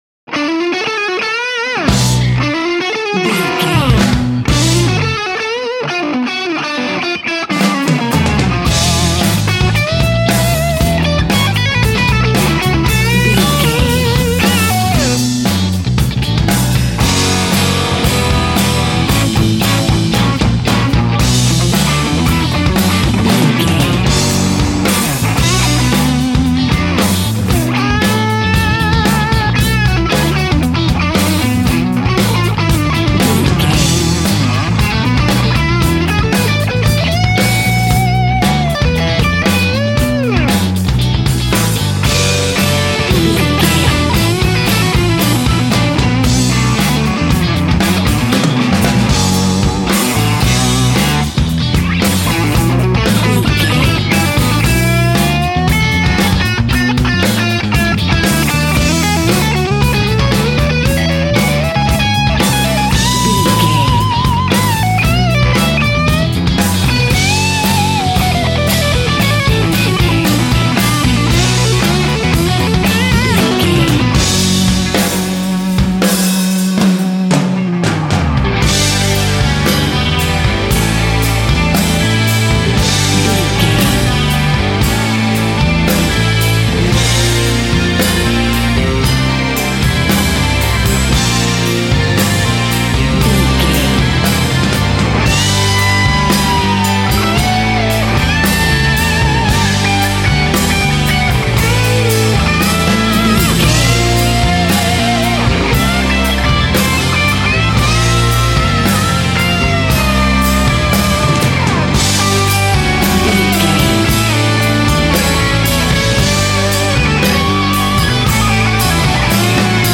Aeolian/Minor
drums
electric guitar
bass guitar
hard rock
lead guitar
aggressive
energetic
intense
nu metal
alternative metal
blues rock